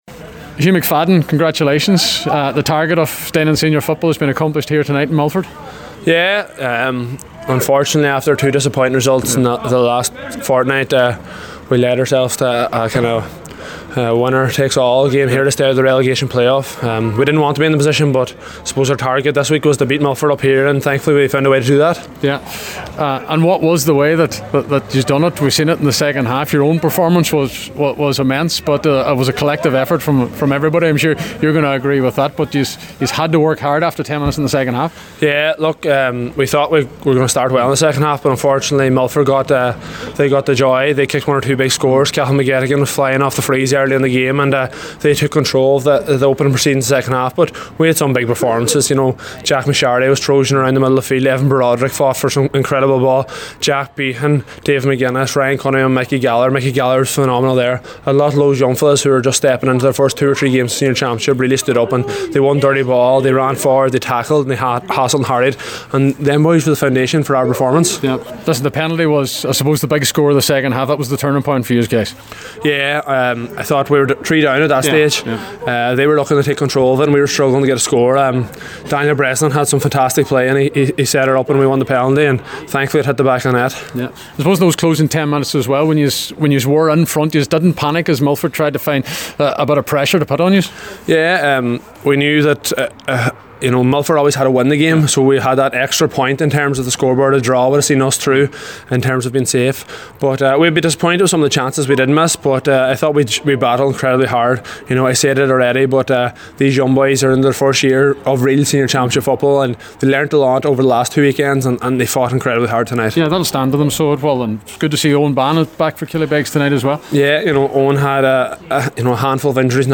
After the match